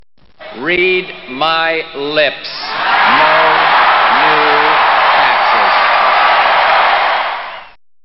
Category: Radio   Right: Personal